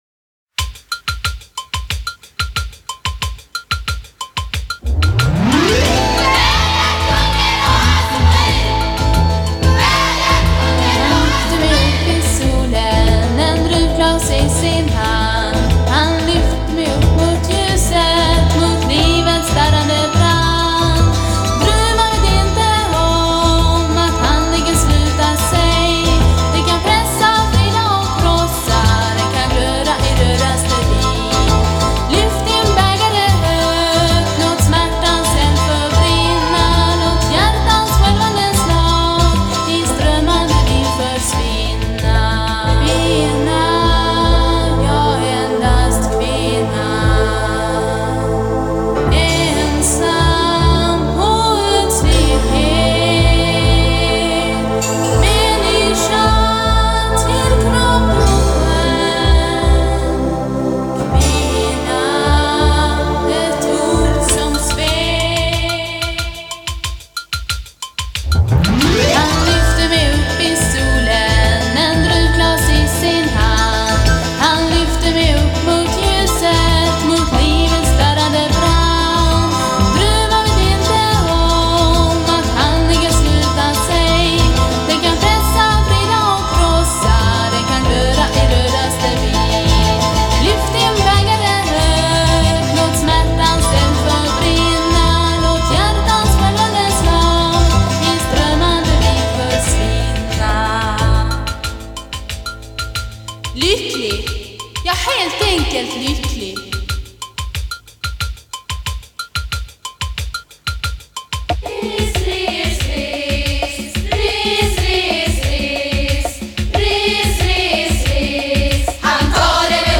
Han var också med och spelade synt på låtarna.
Här är (en del) av musiken till musikalen om Victoria Benedictsson.